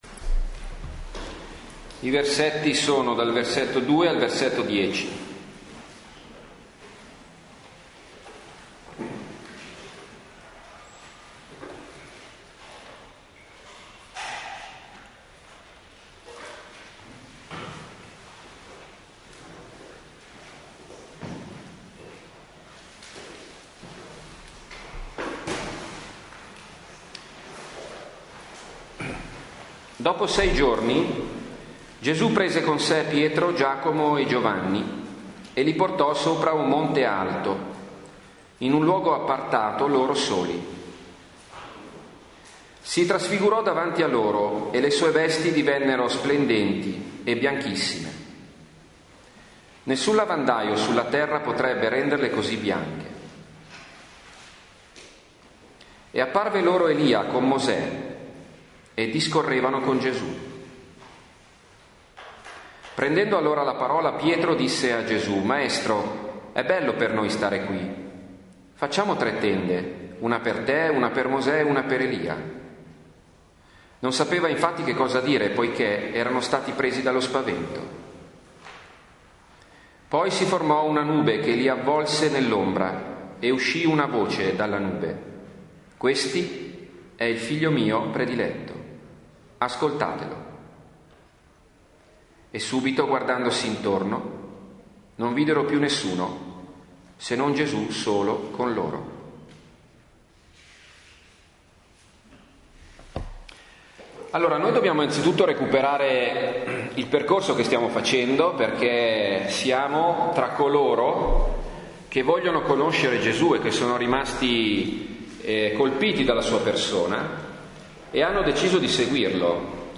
Lectio
durante il pellegrinaggio in terrasanta nell’agosto 2012 con il gruppo famiglie
Lectio-2-Monte-Tabor_01.mp3